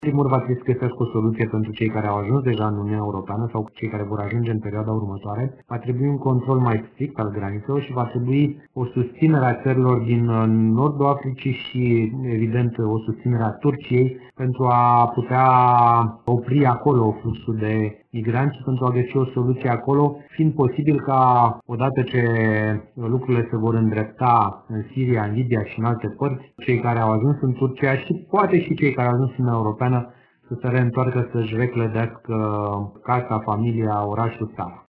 Europa poate gestiona problema imigranţilor, dă asigurări europarlamentarul Cristian Buşoi.